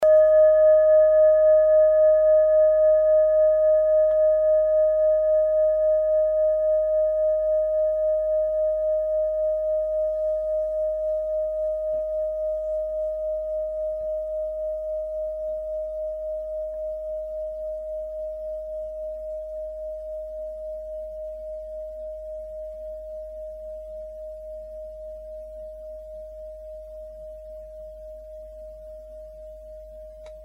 Kleine Klangschale Nr.2
Sie ist neu und ist gezielt nach altem 7-Metalle-Rezept in Handarbeit gezogen und gehämmert worden.
Hören kann man diese Frequenz, indem man sie 32mal oktaviert, nämlich bei 154,66 Hz. In unserer Tonleiter befindet sich diese Frequenz nahe beim "D".
kleine-klangschale-2.mp3